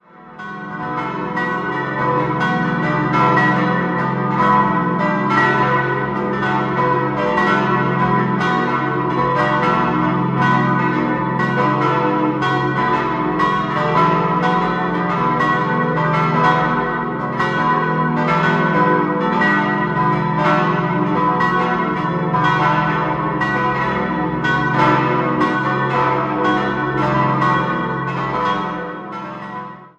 5-stimmiges Geläute: h°-d'-e'-fis'-a' Alle Glocken stammen aus der Gießerei Grassmayr in Innsbruck.